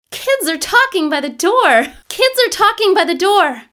cheering.wav